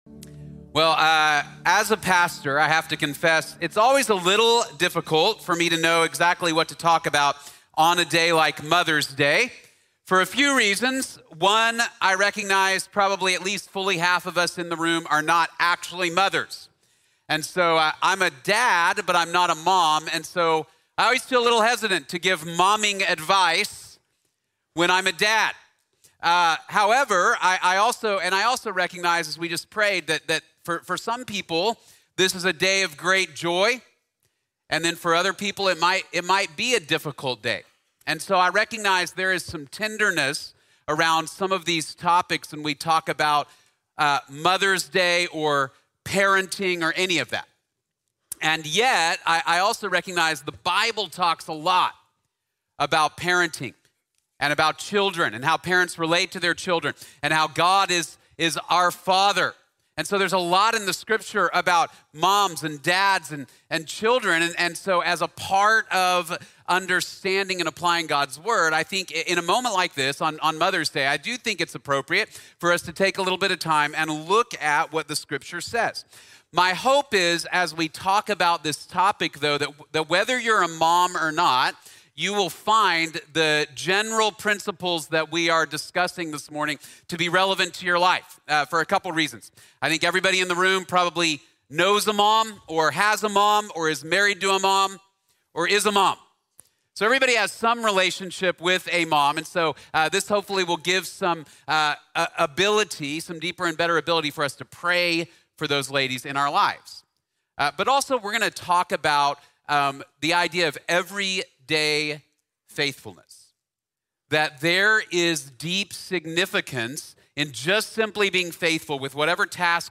Treasure These Things | Sermon | Grace Bible Church